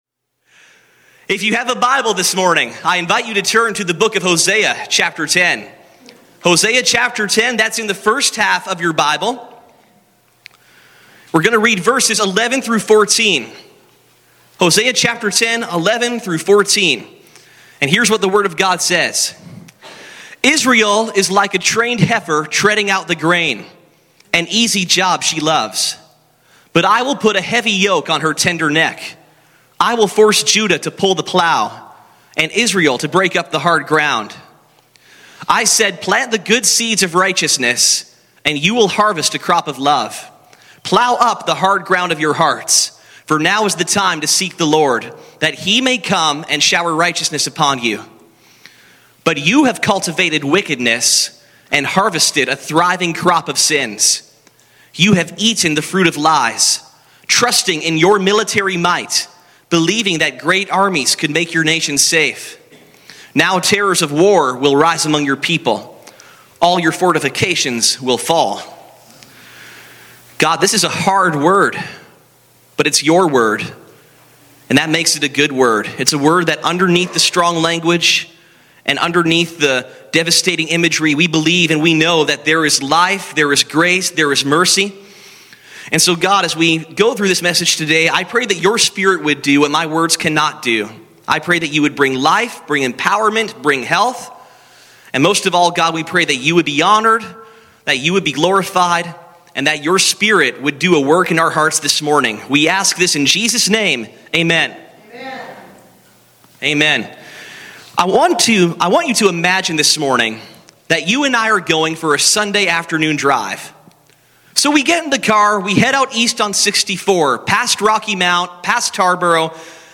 Harvest Jesus obedience perseverance Sunday Morning What kind of harvest are you sowing with your life?